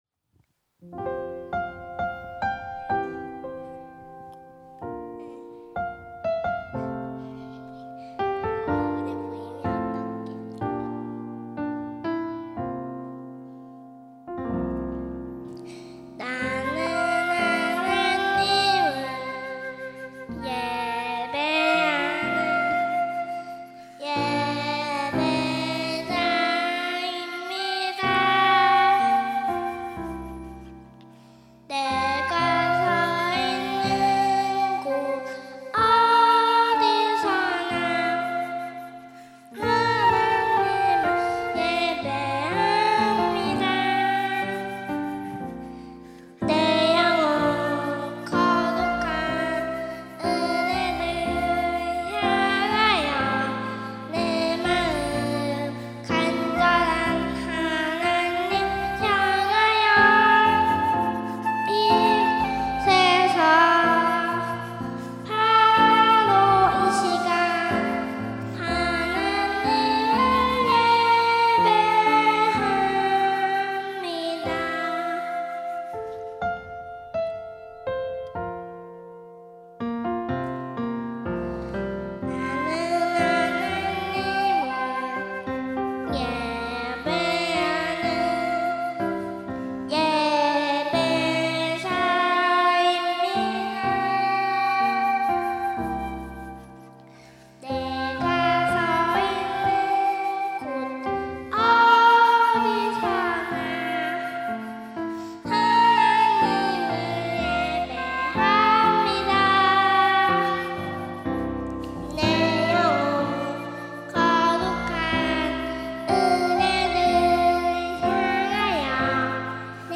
특송과 특주 - 나는 예배자입니다